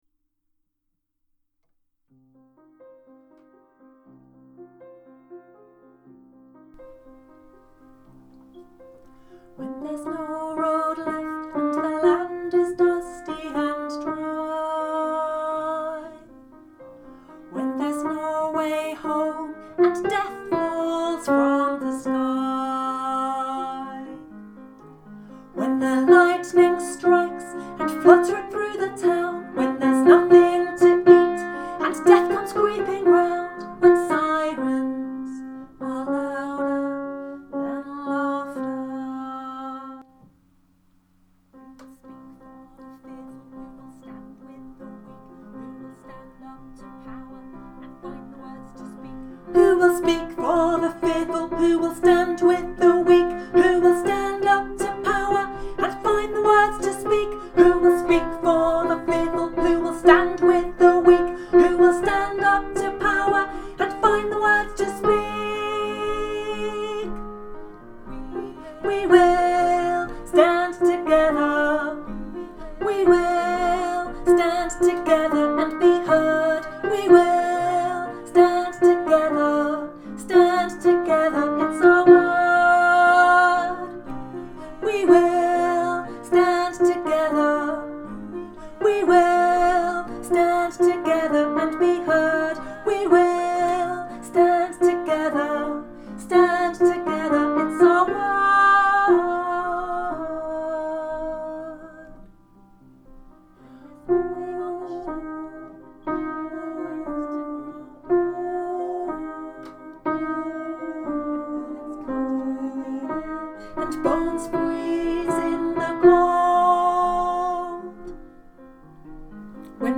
OUR WORD, OUR WORLD - ALTO